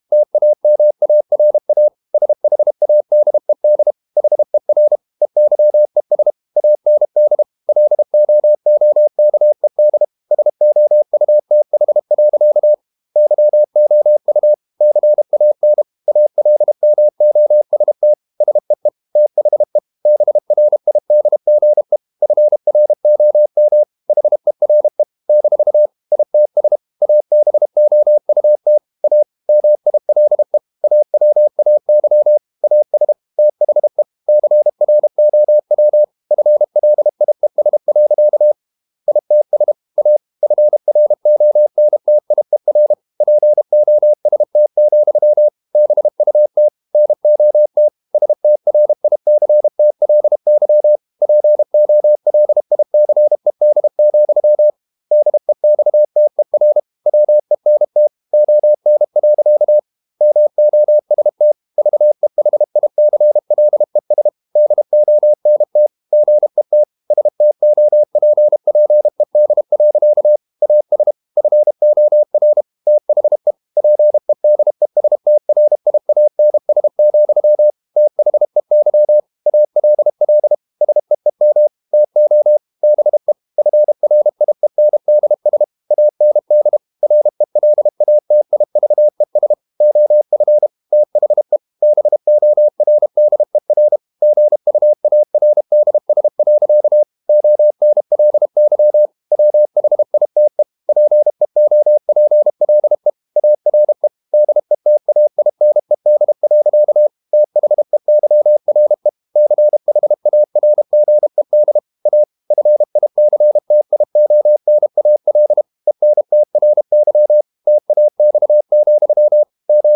Never 32wpm | CW med Gnister